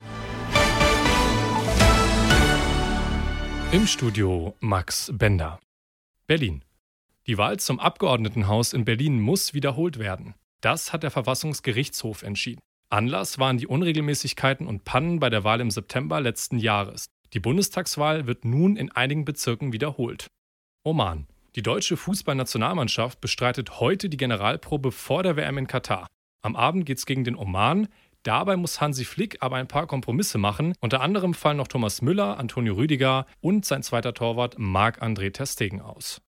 sehr variabel, markant
Mittel minus (25-45)
Radio Nachrichten
Station Voice